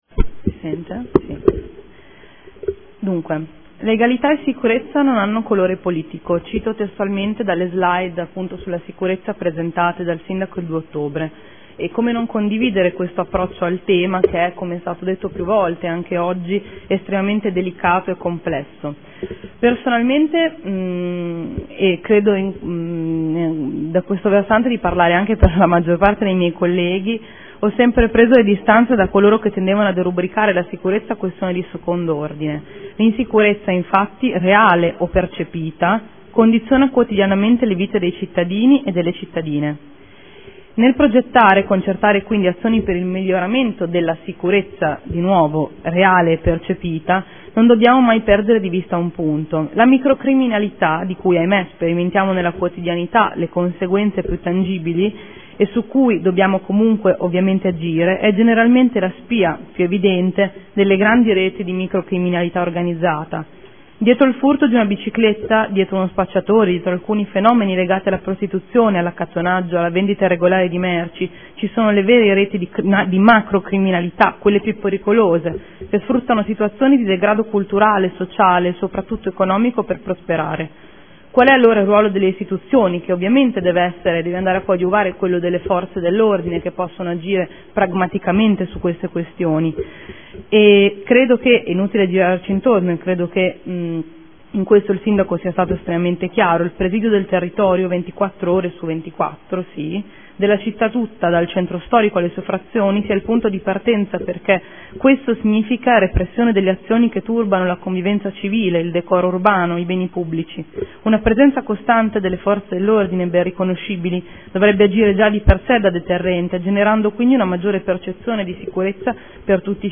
Seduta del 9/10/2014 Dibattito Sicurezza.